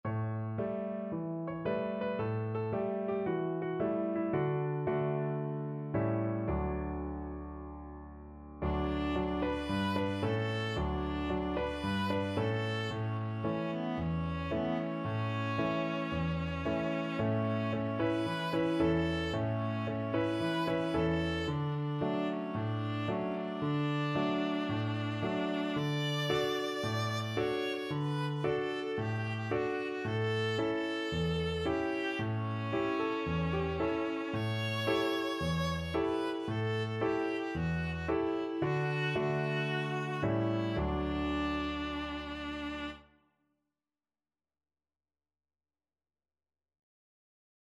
Traditional Trad. Oma Rapeti Viola version
Cheerfully! =c.112
D major (Sounding Pitch) (View more D major Music for Viola )
4/4 (View more 4/4 Music)
Viola  (View more Easy Viola Music)
Traditional (View more Traditional Viola Music)